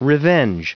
Prononciation du mot revenge en anglais (fichier audio)
Prononciation du mot : revenge